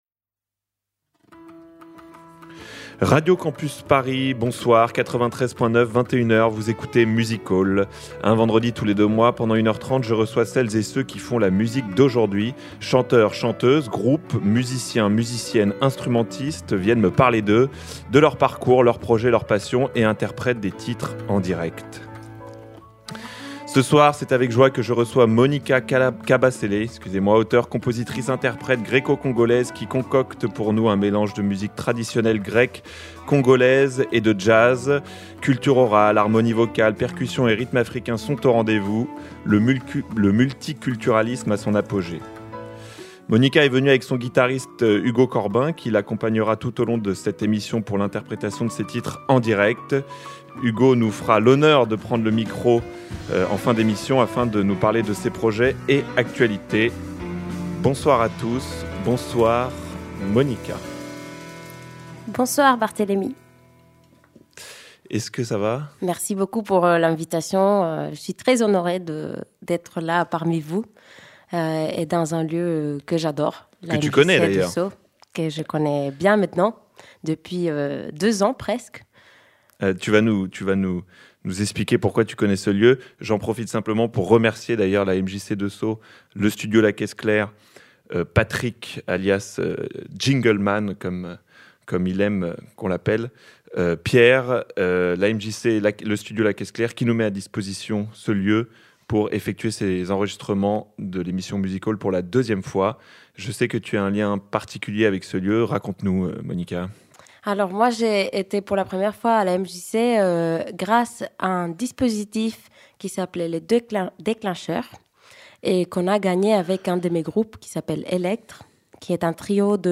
Type Musicale
Pop & Rock